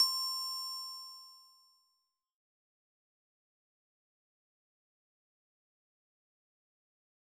Glockenspiel (various songs).wav